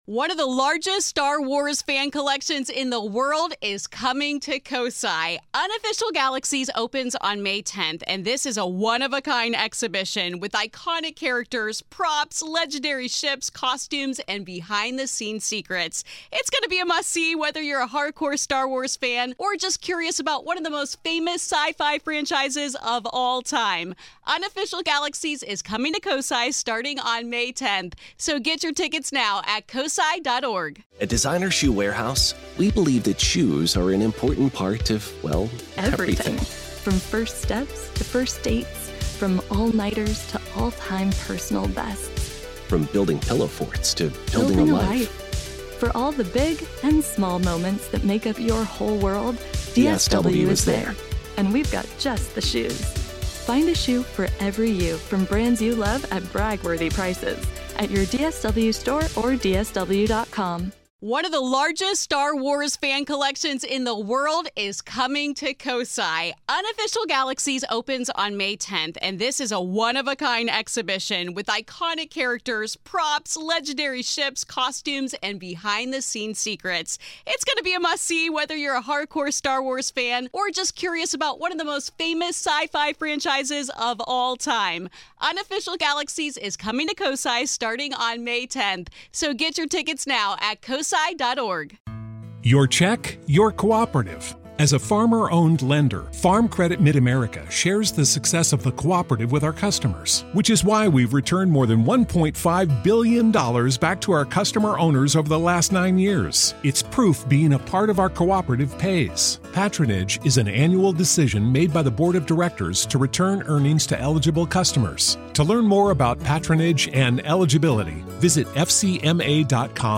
Feeling The Other Side | A Conversation